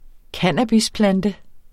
Udtale [ ˈkanˀabis- ]